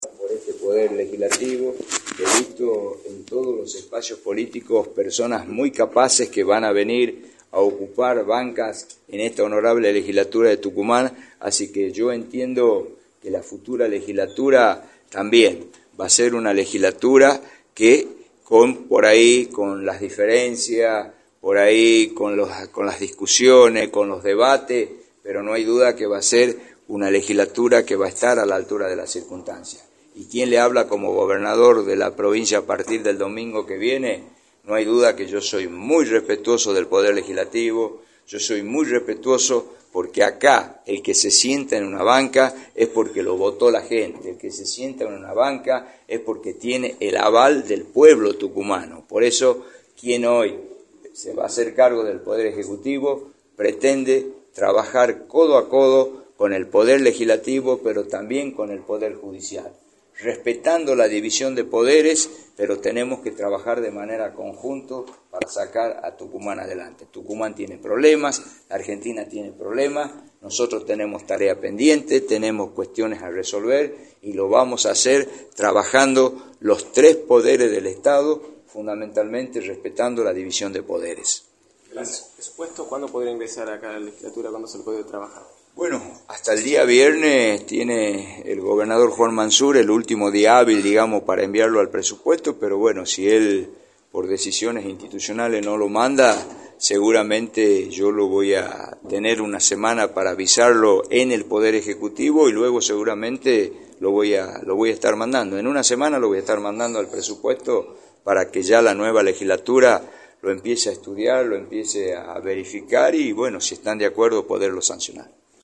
Osvaldo Jaldo, Vicegobernador y Gobernador electo, afirmó en Radio del Plata Tucumán, por la 93.9, que la próxima Legislatura estará a la altura de las circunstancias.
“La futura legislatura va a ser una Legislatura que, con las diferencias, con las discusiones y con los debates, no hay duda que va a ser una Legislatura que va a estar a la altura de las circunstancias, soy muy respetuoso del poder legislativo, soy muy respetuoso porque acá, el que se sienta en una banca es porque lo votó la gente, el que se sienta en una banca es porque tiene el aval del pueblo tucumano” señaló Osvaldo Jaldo en entrevista para “La Mañana del Plata”, por la 93.9.